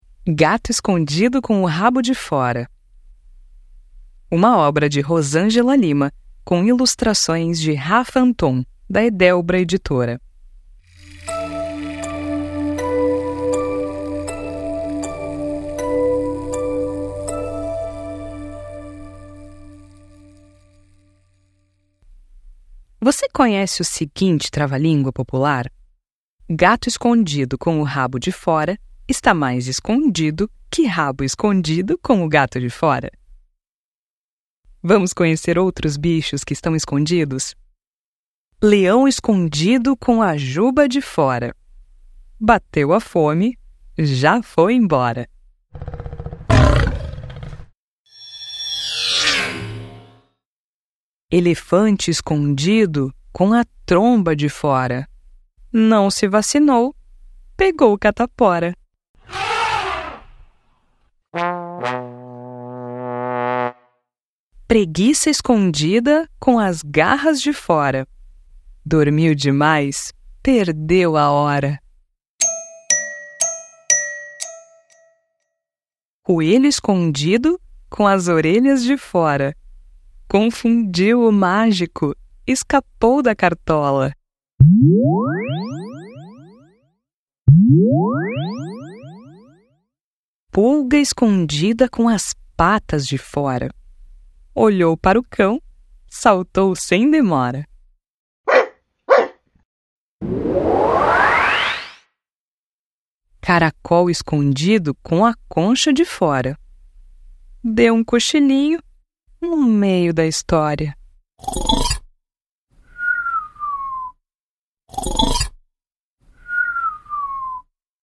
Amostra do audiolivro